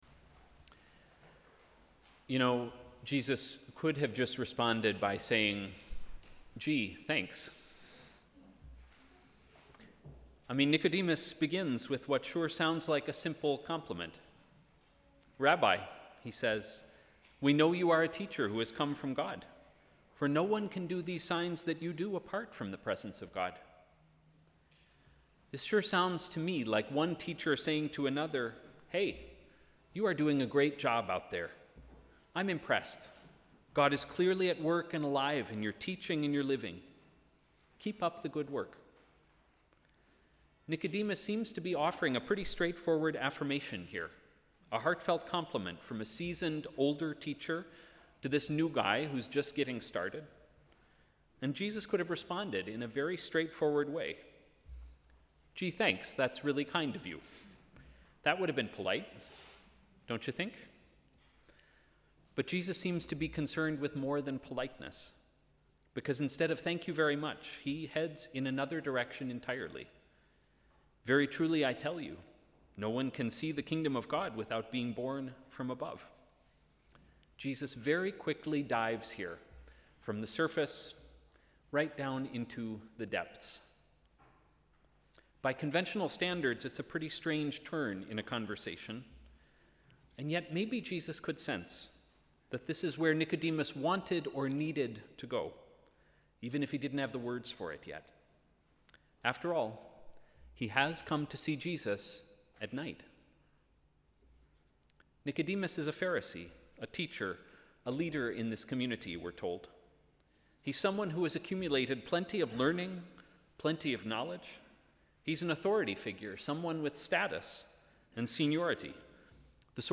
Sermon: ‘From the surface to the depths’